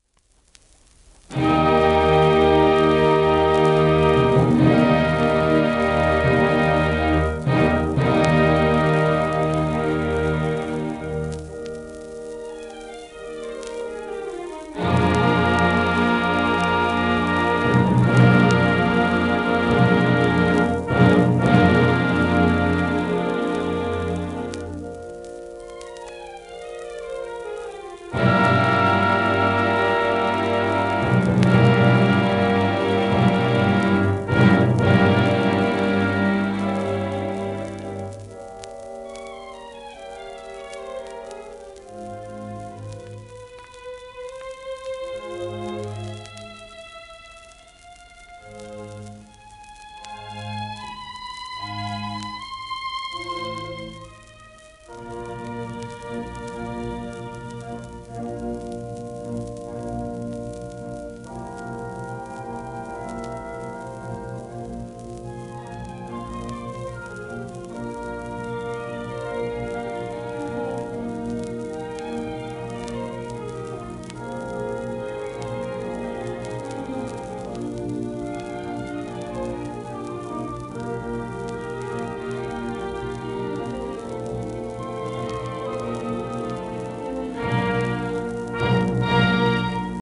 盤質A- *小キズ、アルバム痛み
シェルマン アートワークスのSPレコード